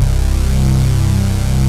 LAYER LEAD 1.wav